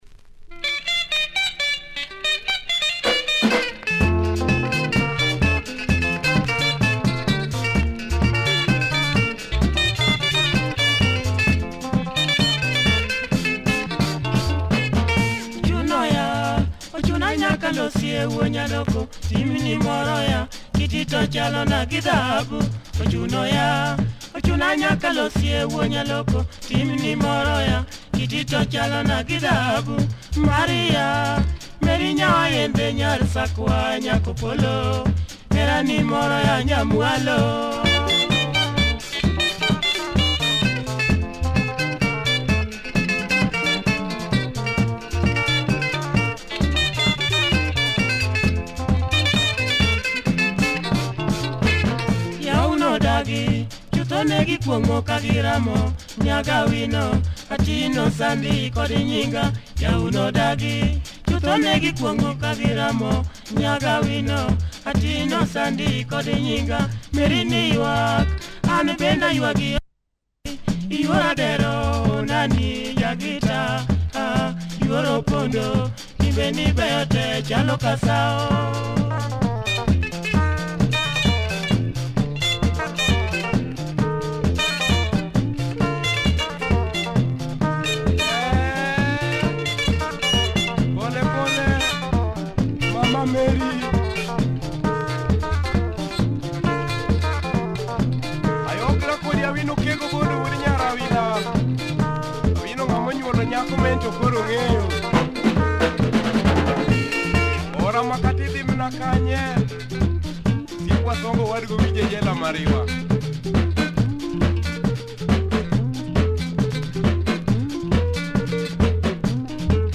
Great luo benga with a good driving groove, and nice vocals.